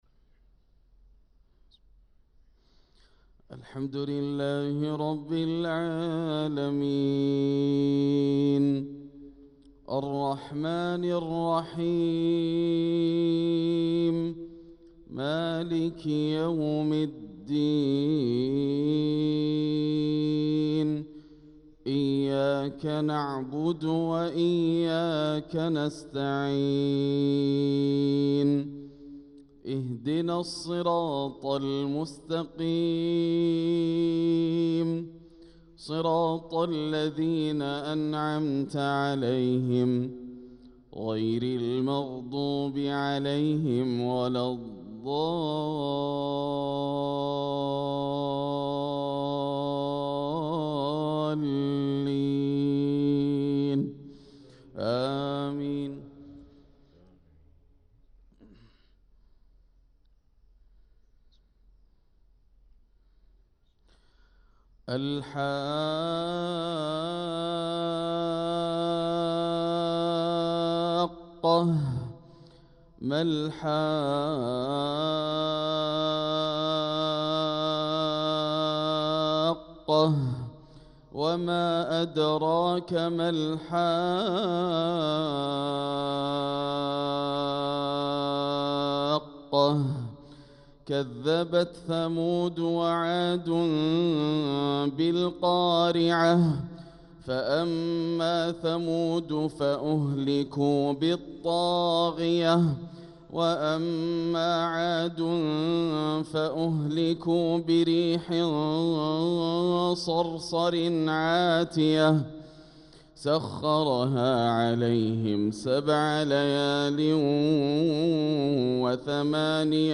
صلاة الفجر للقارئ ياسر الدوسري 16 ربيع الآخر 1446 هـ
تِلَاوَات الْحَرَمَيْن .